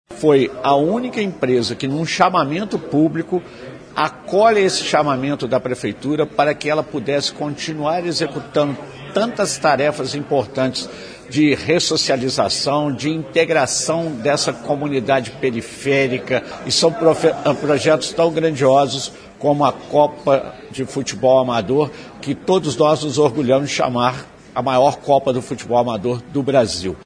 O modelo atende ao chamamento público normatizado pelo Decreto 13.840, de 2020, quando a Prefeitura convocou empresas da cidade a patrocinarem os eventos esportivos, conforme explica o prefeito Antônio Almas.
prefeito Antônio Almas